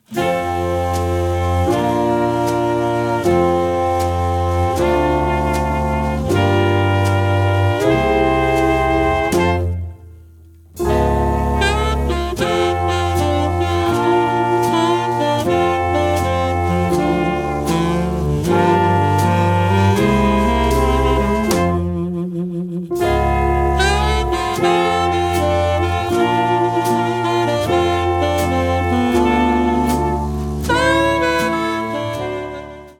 trumpet
trombone
alto sax, baritone sax, clarinet, vocals
piano
guitar
bass
drums